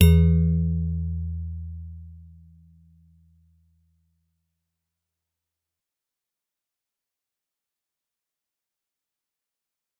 G_Musicbox-E2-f.wav